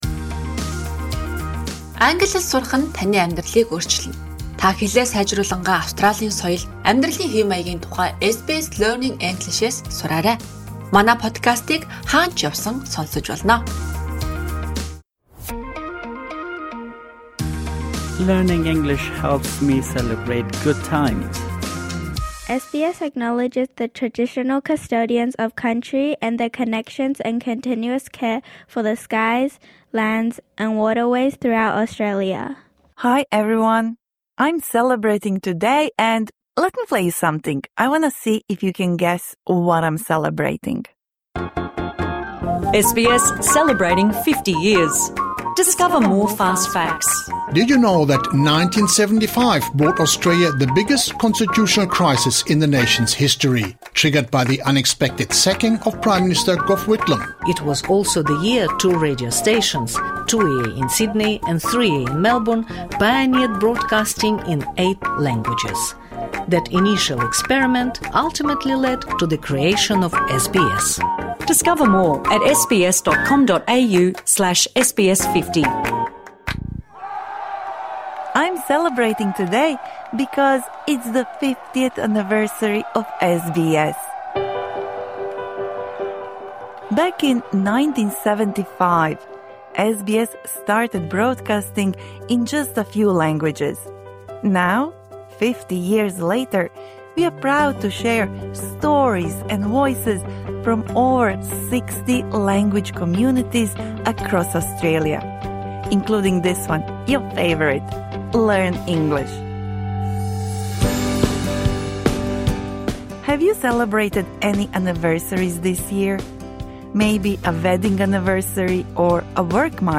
Learning notes Lesson learning objective: Learn how to congratulate someone on their work or wedding anniversary.